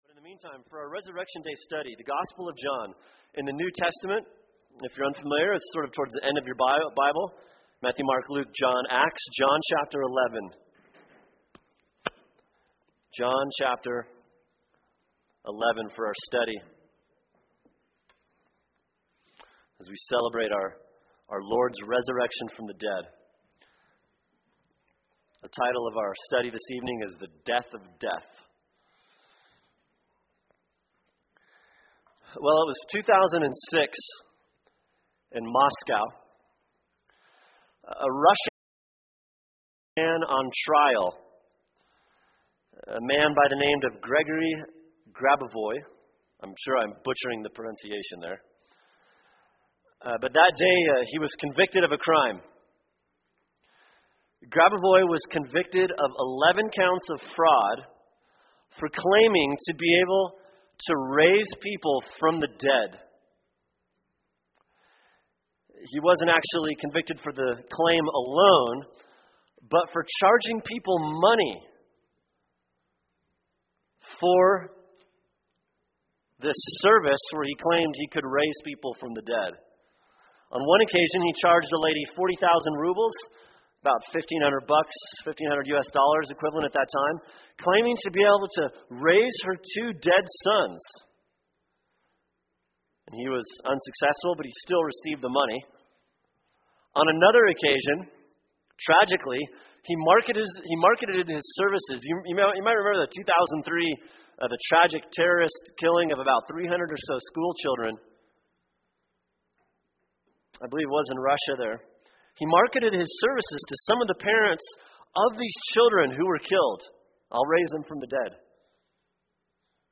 [sermon] John 11 “The Death of Death” | Cornerstone Church - Jackson Hole